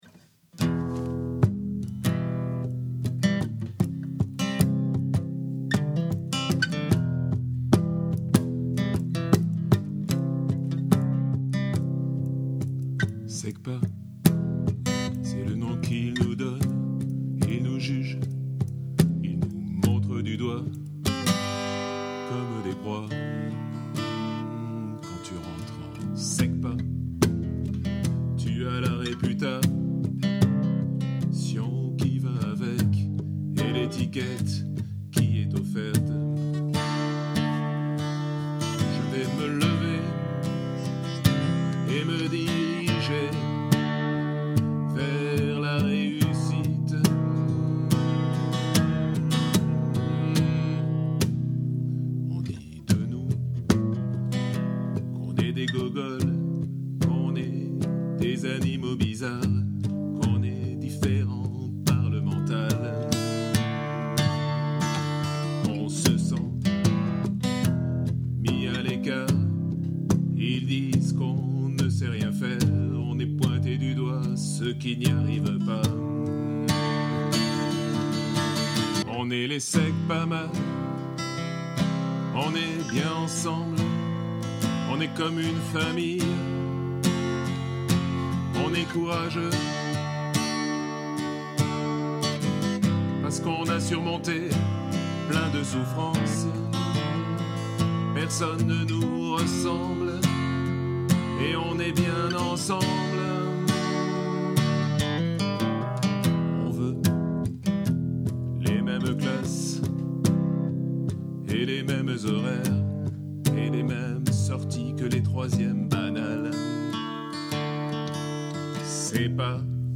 Segpa blues.mp3